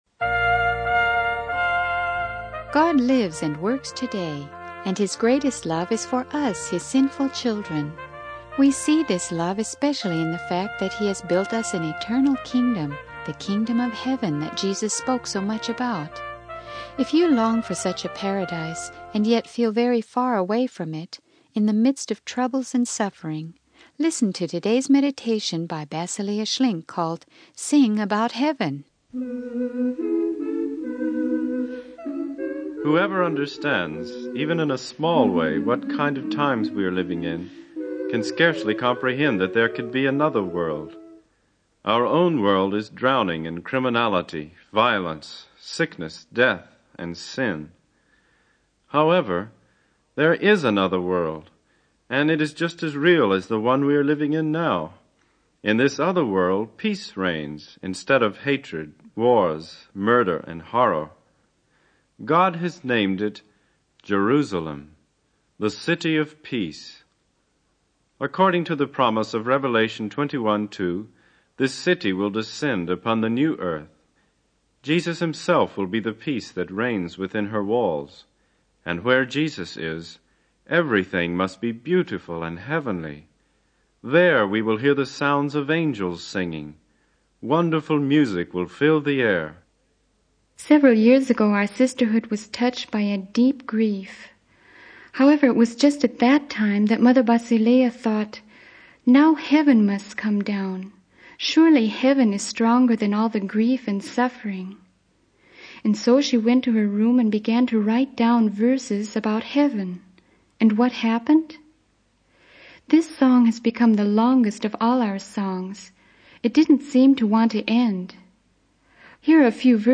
The sermon emphasizes the importance of focusing on the eternal kingdom of heaven and its joy and glory to drown out earthly suffering.
Sermon Outline